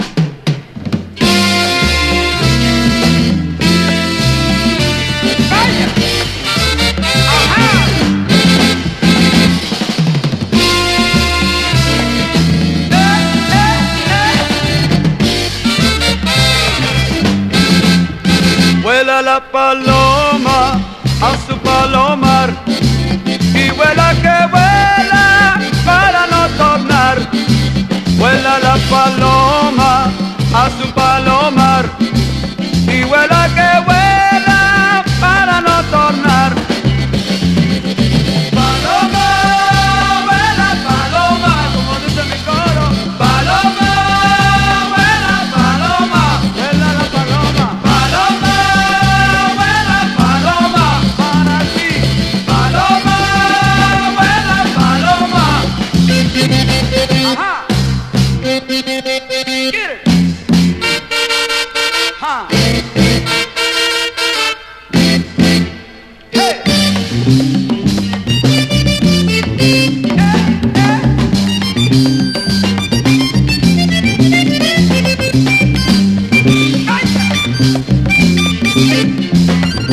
WORLD / LATIN / TEX MEX / TEJANO / LATIN FUNK / CHICANO
夕暮れ系の優しいスウィート・メロウ・グルーヴ